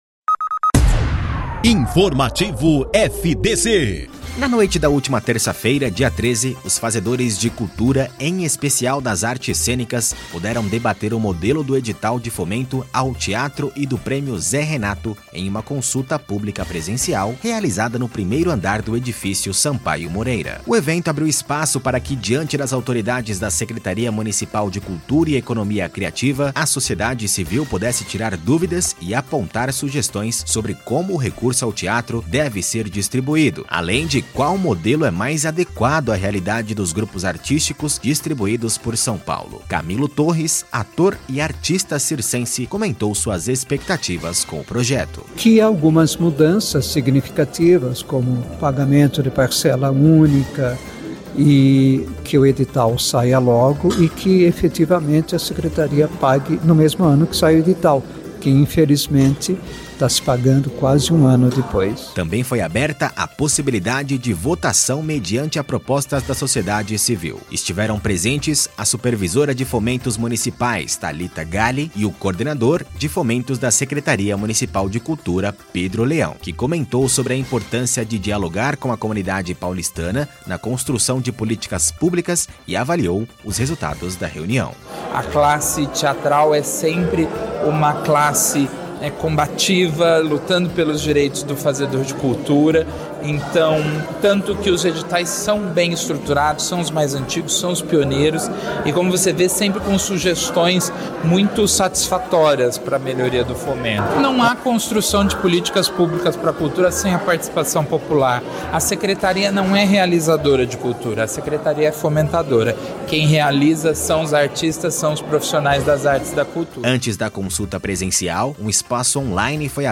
Ouça a notícia: Consulta Pública presencial é realizada para debater o Fomento ao Teatro